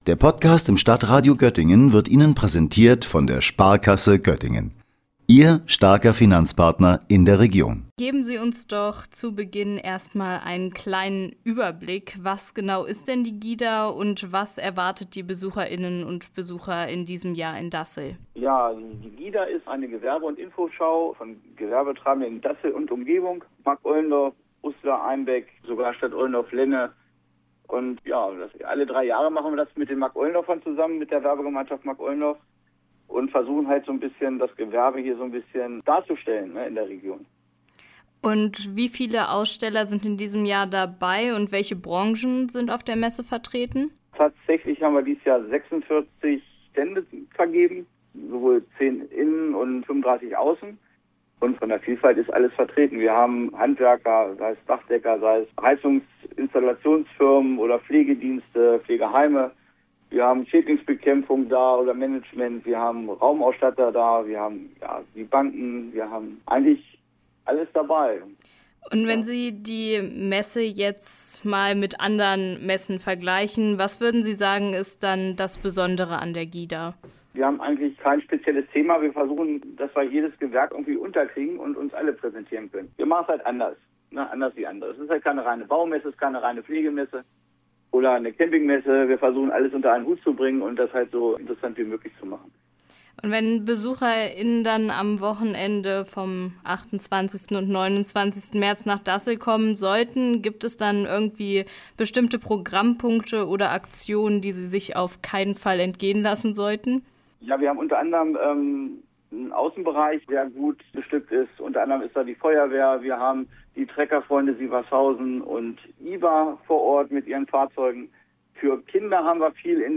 Was genau Sie in diesem Jahr auf der GHIDA erwartet und für wen sich ein Besuch besonders lohnen könnte, hören Sie nun